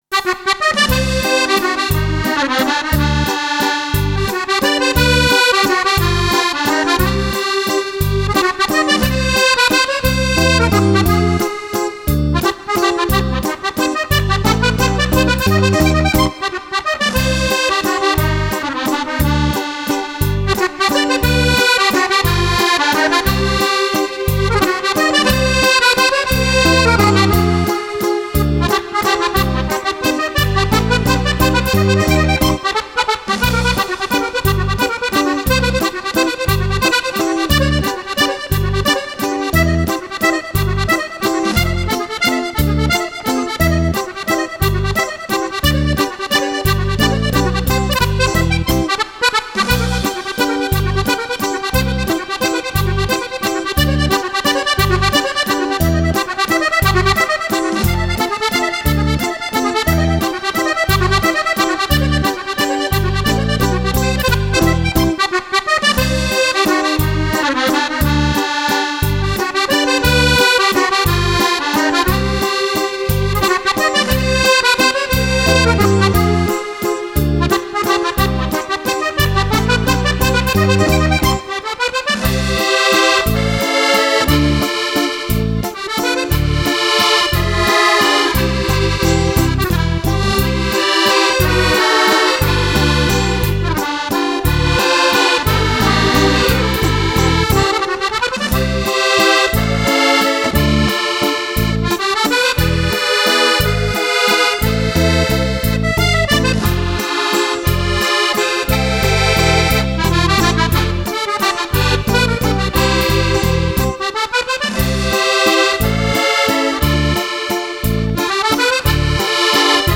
Valzer
14 ballabili per fisarmonica solista
Registrato in Home Recording